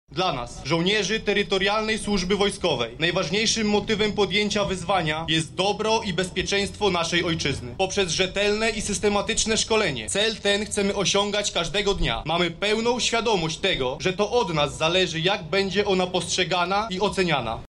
Słowa wojskowej przysięgi wypowiedziane zostały na Placu zamkowym w Lublinie w obecności Ministra obrony Narodowej, dowódców wojskowych oraz władz samorządowych.
Dlaczego młodzi chcą służyć mówi szeregowy